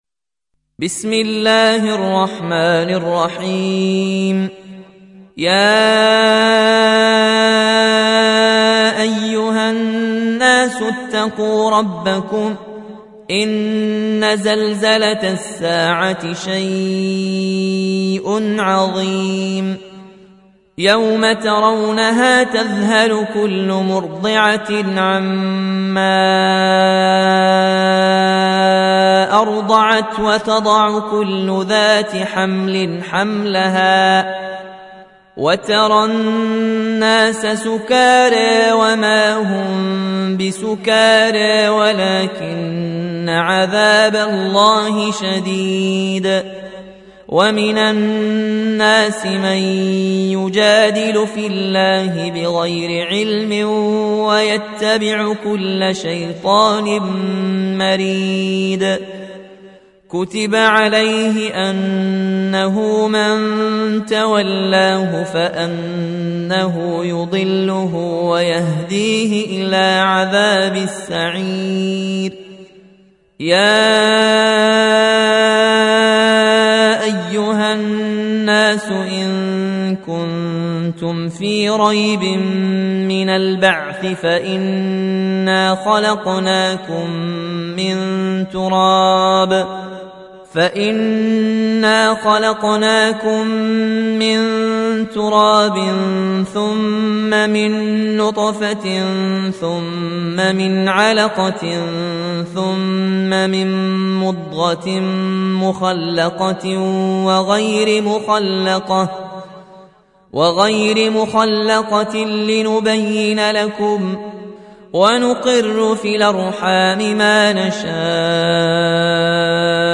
روایت ورش از نافع